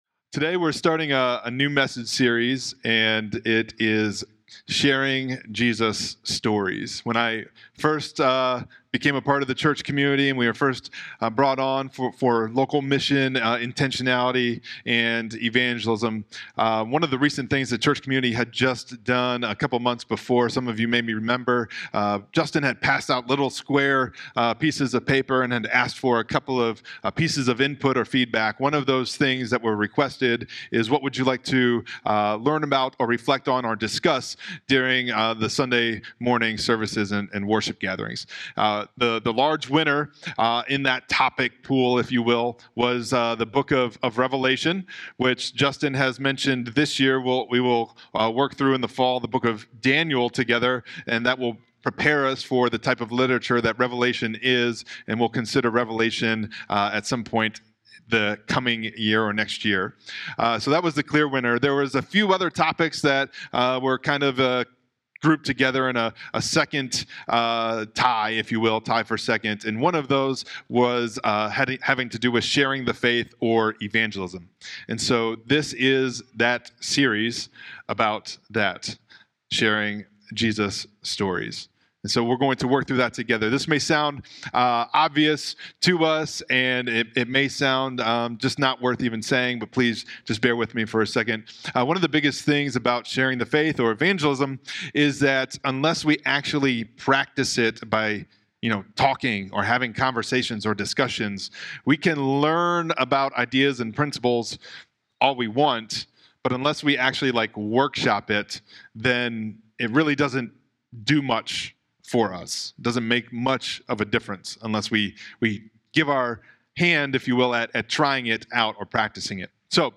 Sermons | First Church Bellevue